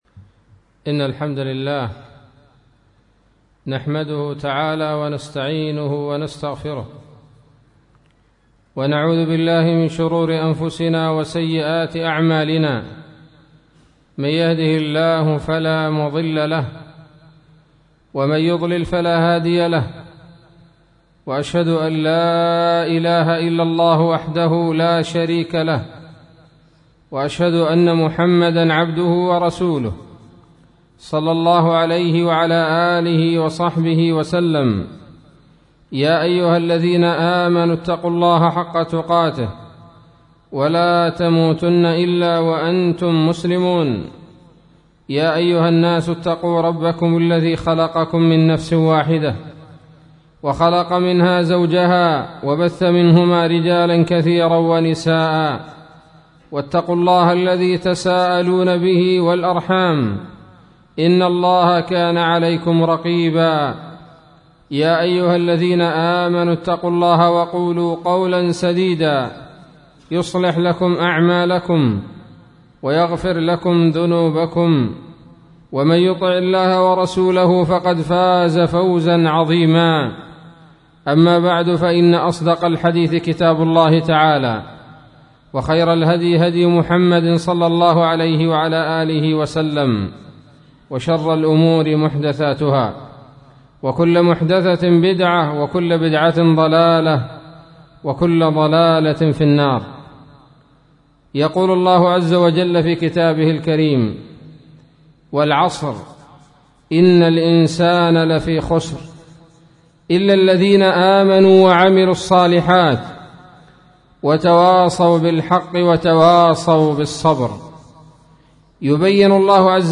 خطبة بعنوان : ((ثمار الإيمان))29 صفر 1437 هـ